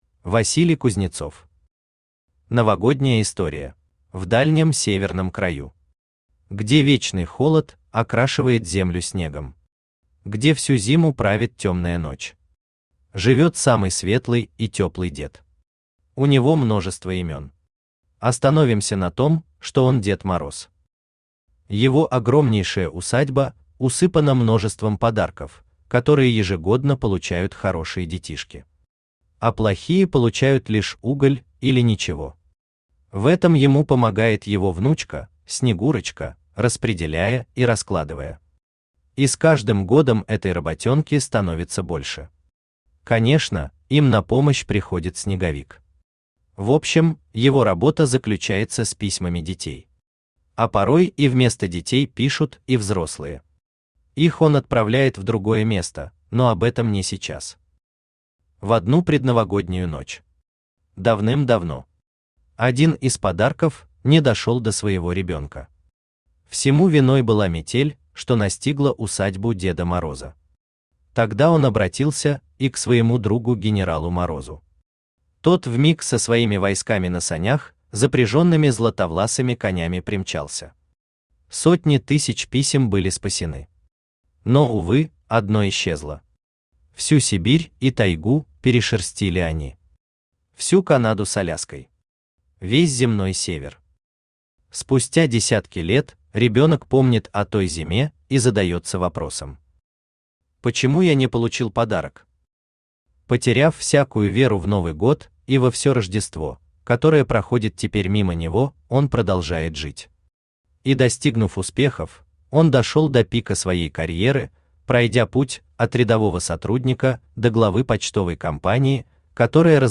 Аудиокнига Новогодняя история | Библиотека аудиокниг
Aудиокнига Новогодняя история Автор Василий Игоревич Кузнецов Читает аудиокнигу Авточтец ЛитРес.